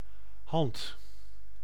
Ääntäminen
Synonyymit handdruk Ääntäminen : IPA: [ɦɑnt] Haettu sana löytyi näillä lähdekielillä: hollanti Käännös Konteksti Ääninäyte Substantiivit 1. hand of a human or other simian anatomia 2. hand UK US Suku: f .